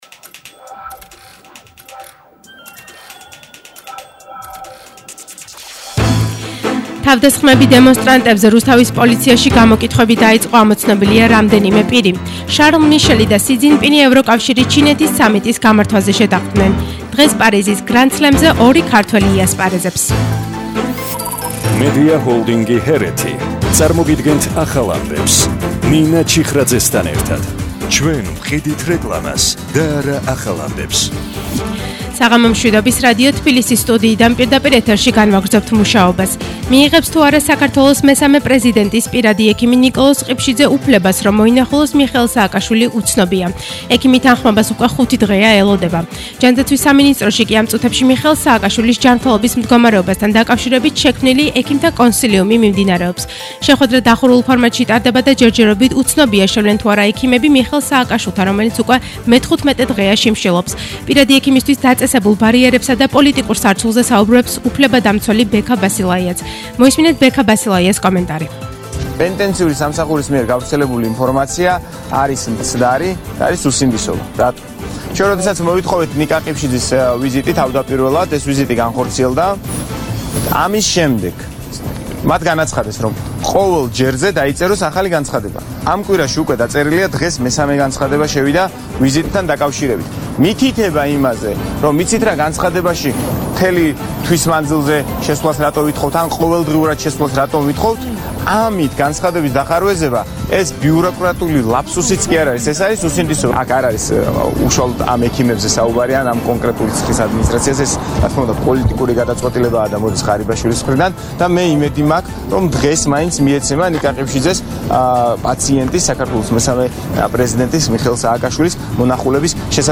ახალი ამბები 20:00 საათზე –15/10/21